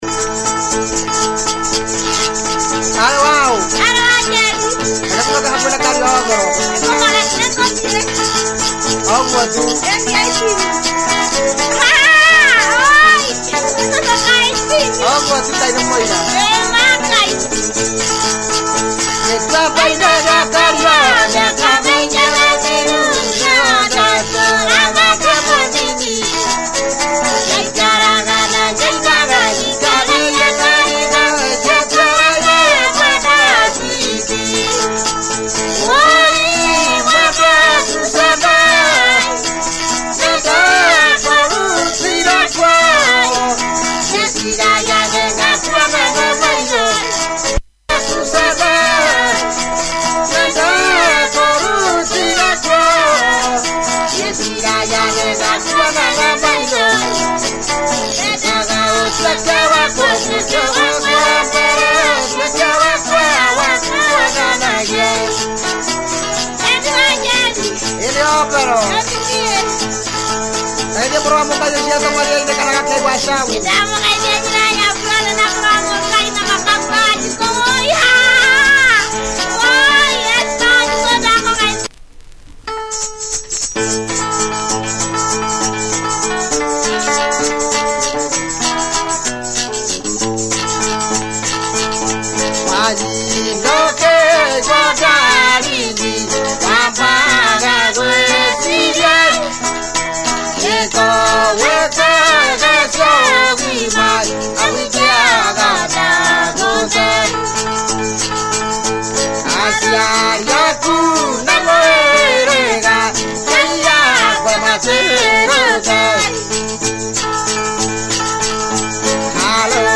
Good early Kikuyu Benga traditional crossover
Duo harmony style and a lot of razzle, check audio!